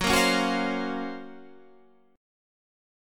GbM#11 chord